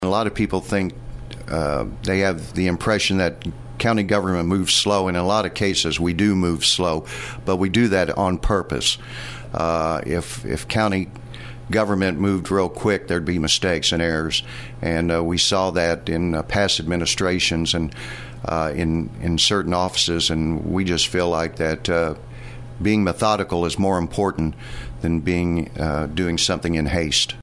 Louie Sieberlich, St. Francois County Auditor, sat down with KFMO to explain his office's work and what they do on a day-to-day basis.